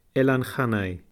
Pronunciation[ˈkʰanaj] ,
[ˈelan ˈxanaj]